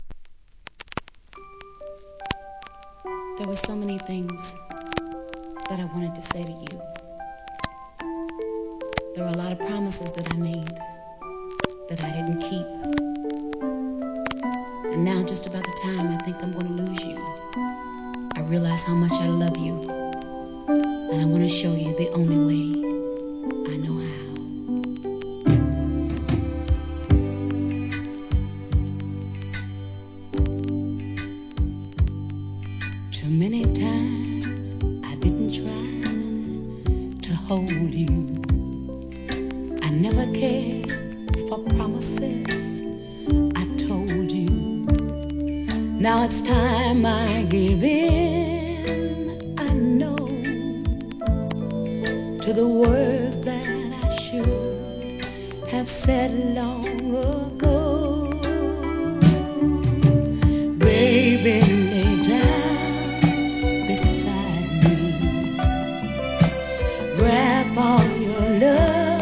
C (冒頭周期的なノイズ)
1. SOUL/FUNK/etc...